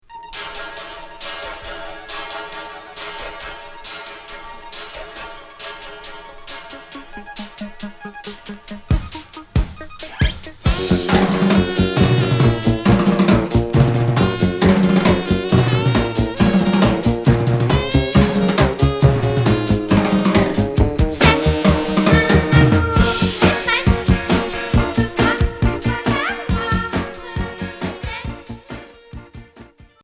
New wave Premier 45t retour à l'accueil